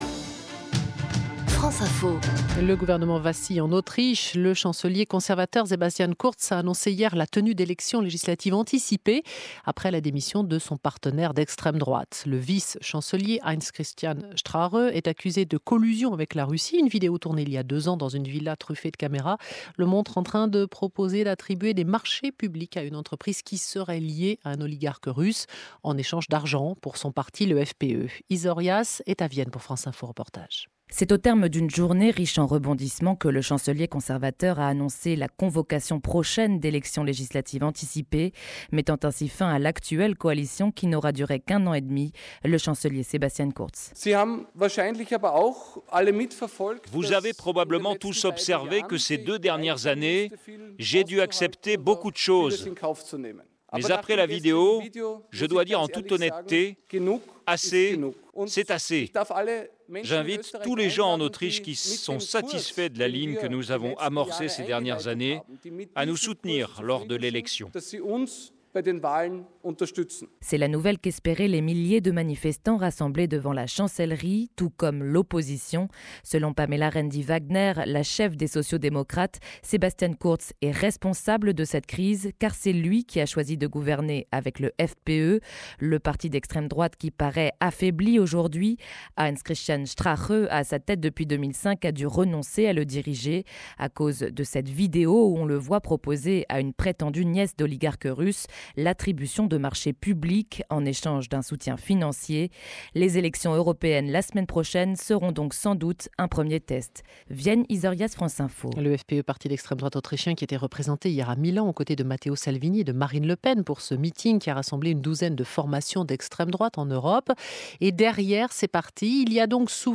répond aux questions de France Info
franceinfo-radio-poutine_et_populismes-1-1.mp3